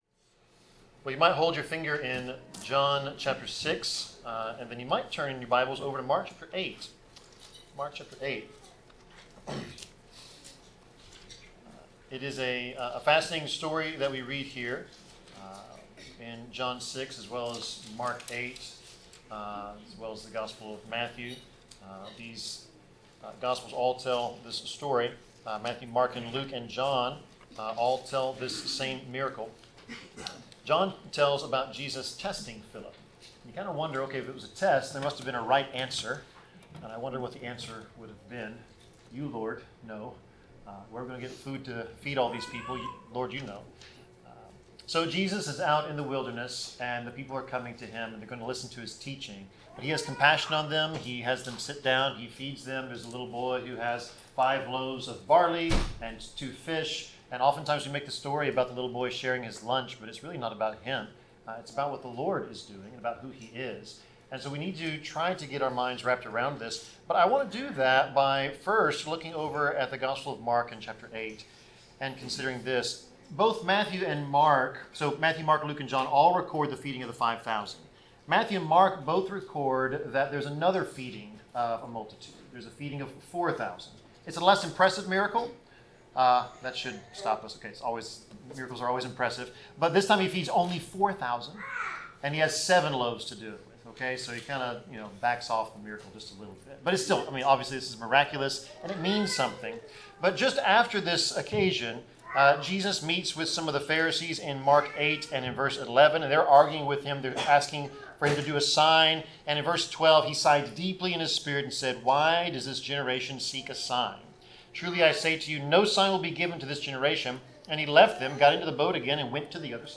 Passage: John 6:1-14; Mark 8:14-30 Service Type: Sermon